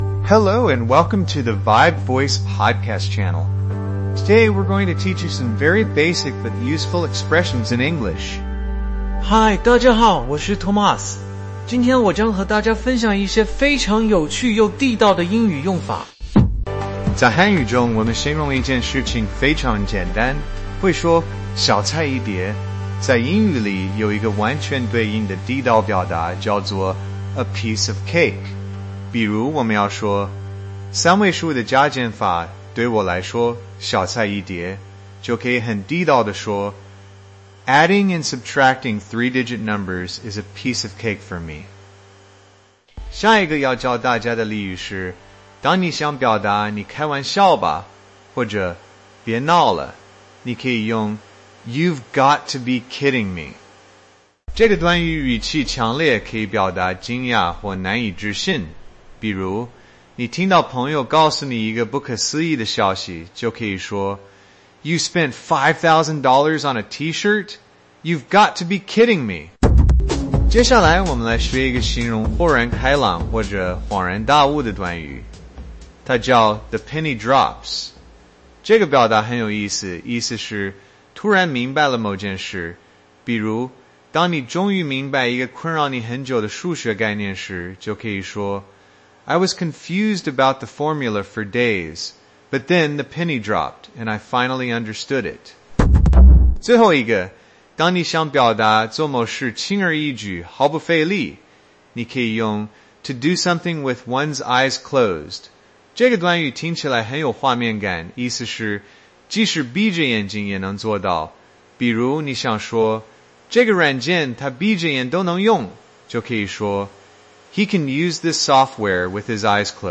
Microsoft выпустила модель с открытым исходным кодом VibeVoice-1.5B для преобразования текста в речь.
Модель имитирует естественный разговор, но без перебиваний. При этом нейросеть не умеет добавлять фоновые звуки, музыку и звуковые эффекты.
Пример работы нейросети с ИИ-разговором, в котором участвуют несколько человек